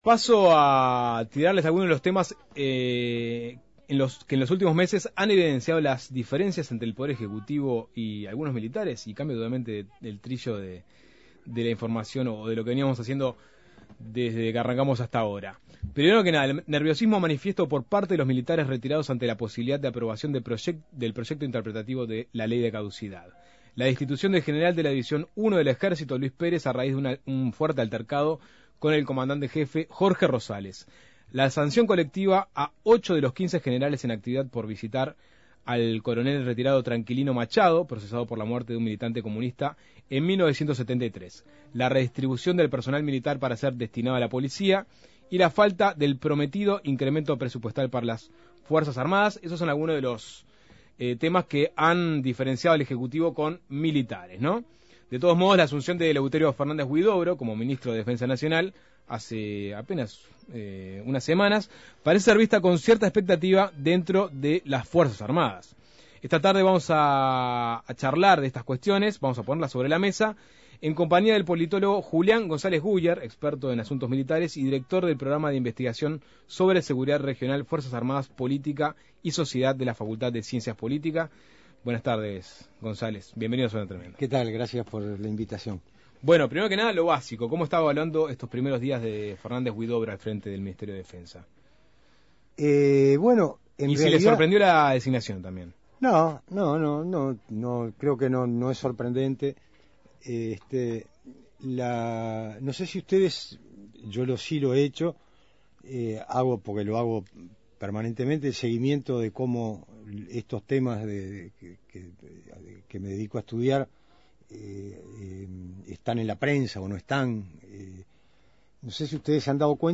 Suena Tremendo Entrevista